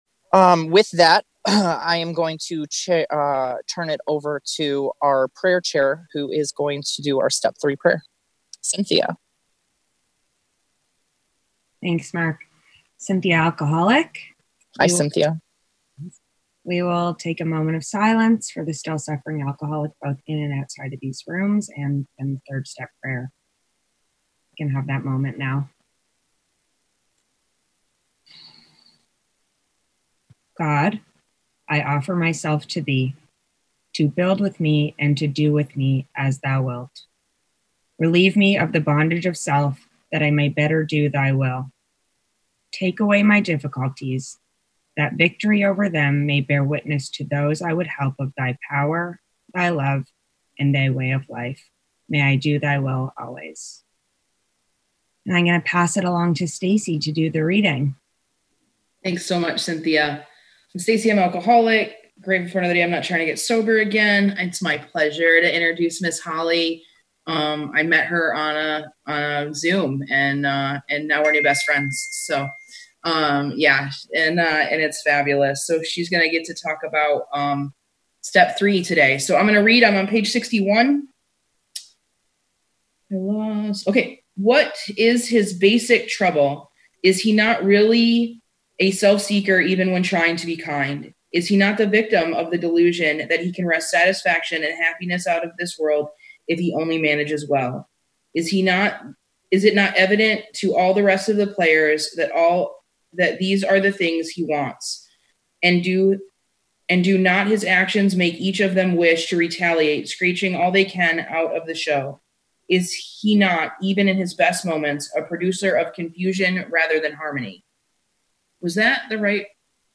Woodstock Conference Young People In AA Sterling Heights MI